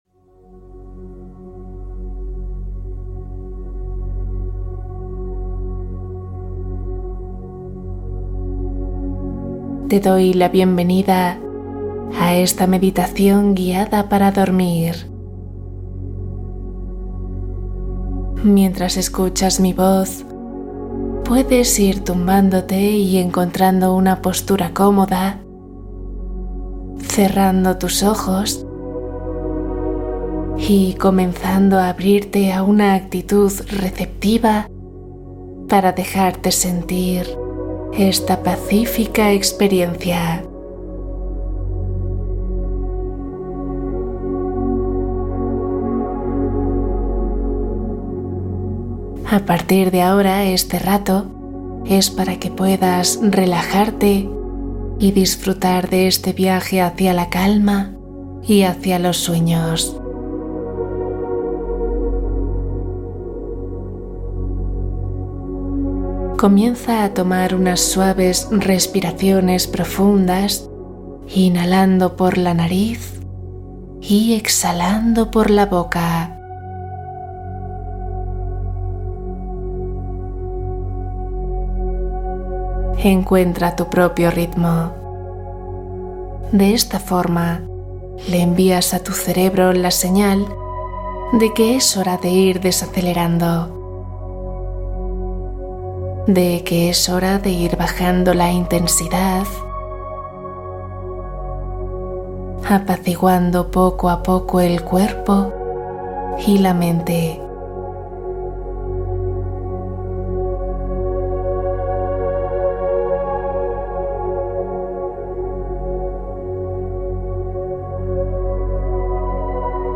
Meditación + cuento Relajación profunda para descansar